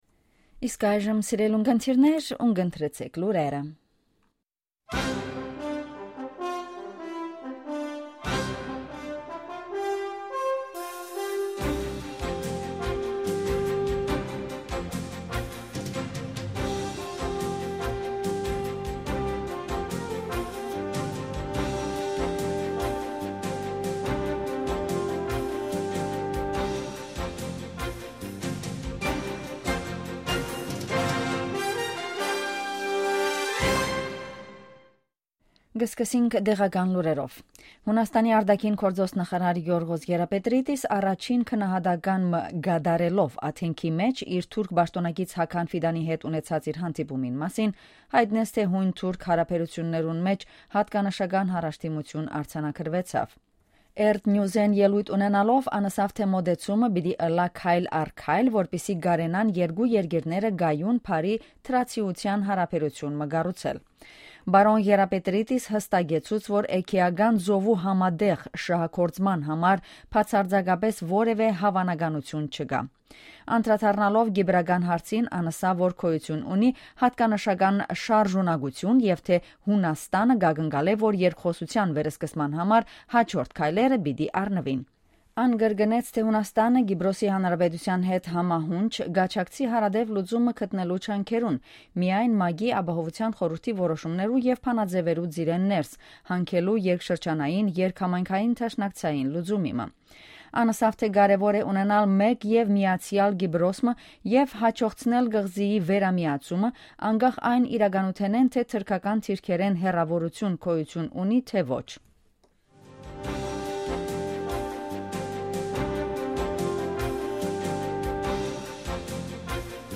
Ειδήσεις στα Αρμένικα - News in Armenian
Καθημερινές Ειδήσεις στα Αρμένικα.